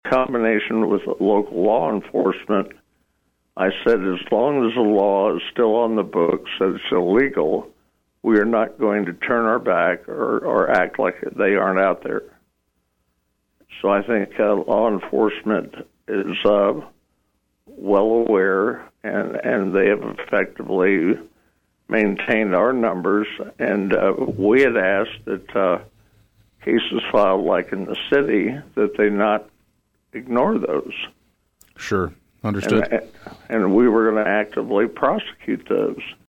With retirement ahead by the end of the calendar year, Lyon County Attorney Marc Goodman reiterated longstanding concerns about drug enforcement policy during the KVOE Talk of Emporia on Friday.